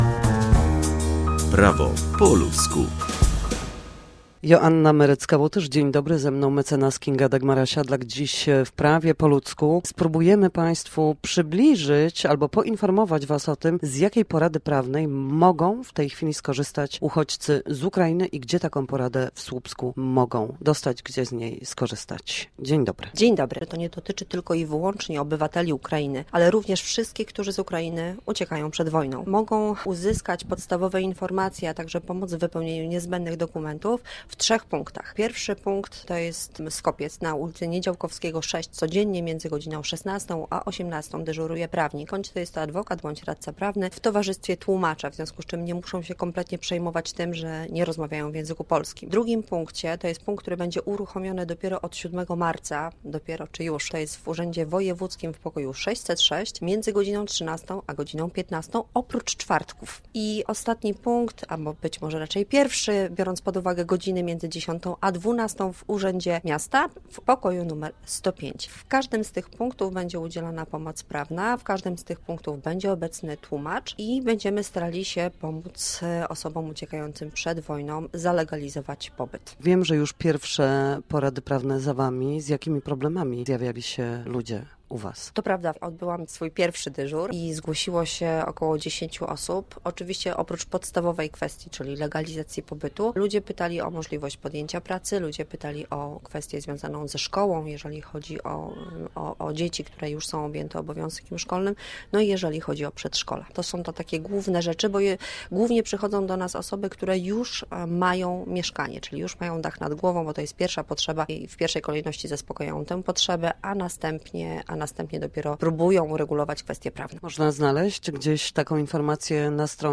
Nasi goście, prawnicy, odpowiadają na jedno pytanie dotyczące zachowania w sądzie, podstawowych zagadnień prawniczych czy pobytu na sali sądowej.